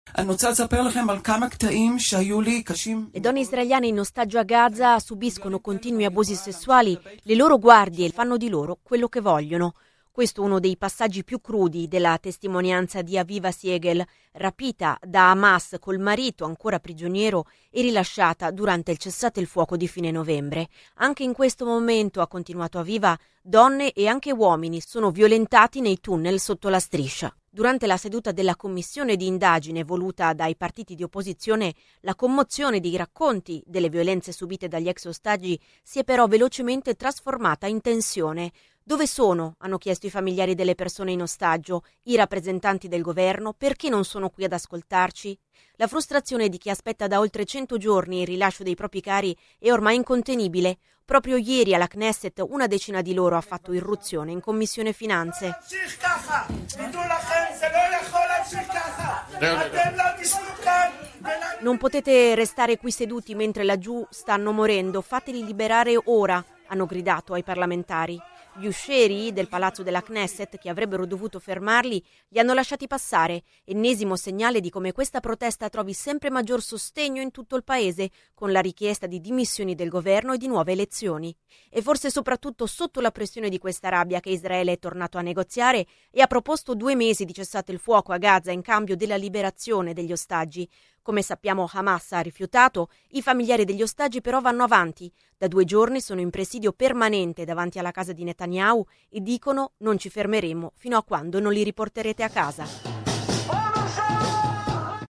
Il racconto della giornata di martedì 23 gennaio 2024 con le notizie principali del giornale radio delle 19.30. I bombardamenti nel sud della striscia di Gaza in queste ore si sono intensificati e l’esercito israeliano ha completamente accerchiato la città di Khan Younis.